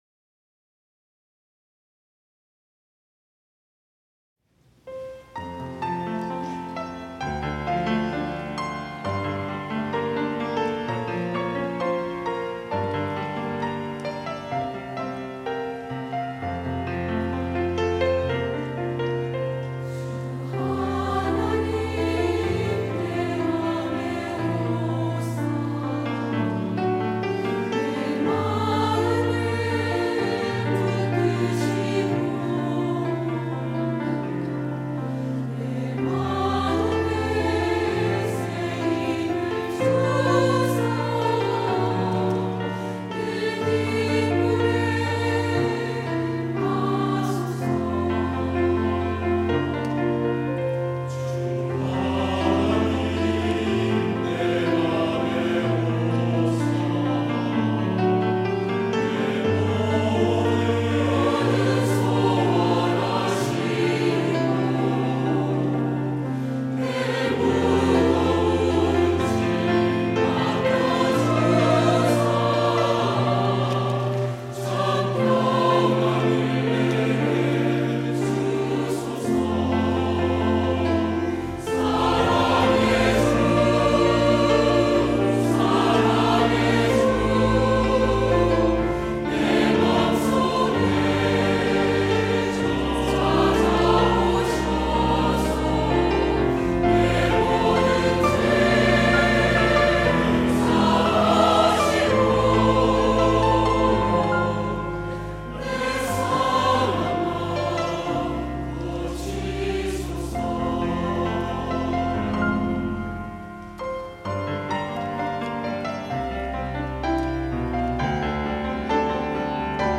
할렐루야(주일2부) - 주 하나님 내 맘에 오사
찬양대